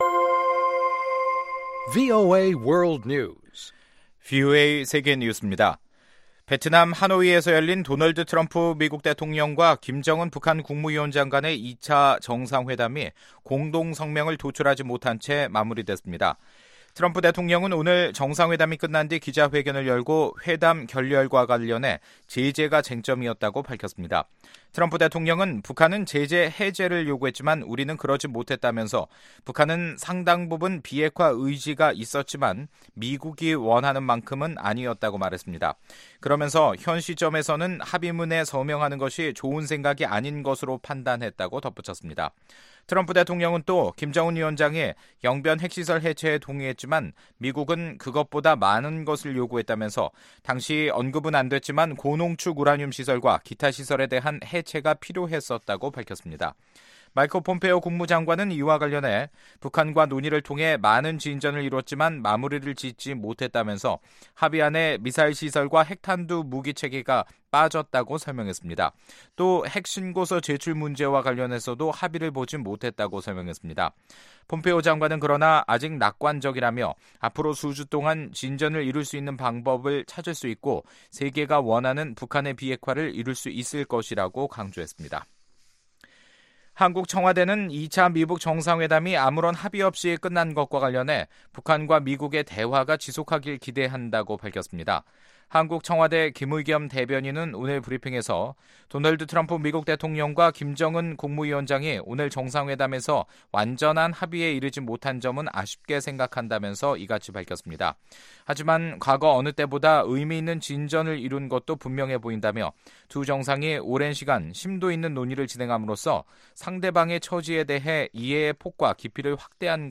VOA 한국어 간판 뉴스 프로그램 '뉴스 투데이', 2019년 21월 26일 2부 방송입니다. 베트남 하노이에서 열린 제 2차 미북 정상회담은 아무런 합의 없이 결렬됐습니다. 북한의 인권을 촉진하기 위한 연례 ‘북한자유주간’ 행사가 4워람ㄹ 워싱턴에서 개최됩니다.